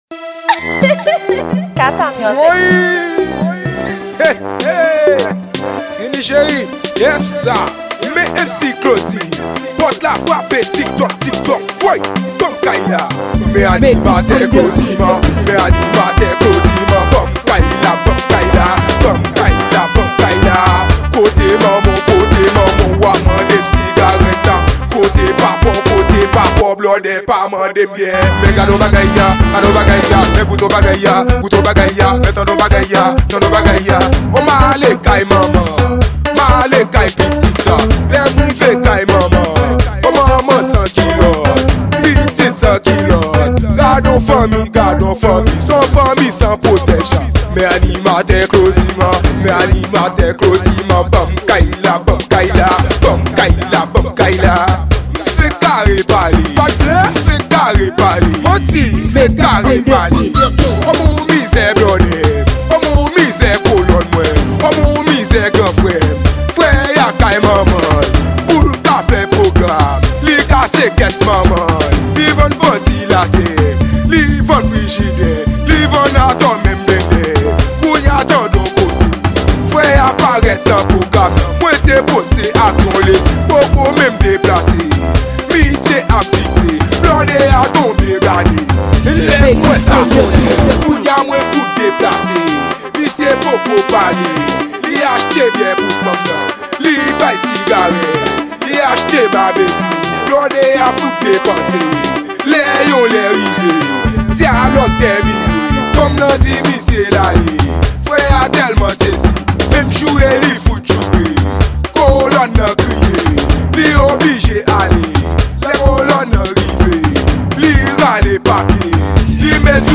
Genre: RABODAY.